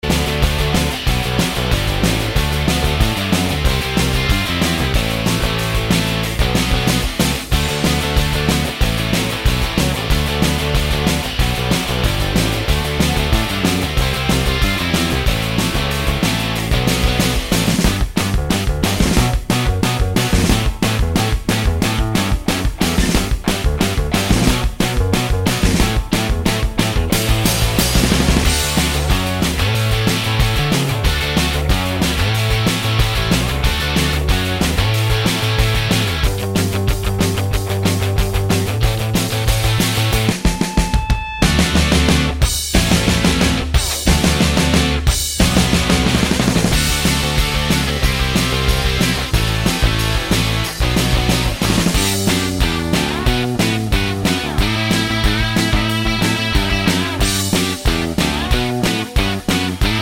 no Backing Vocals Punk 2:55 Buy £1.50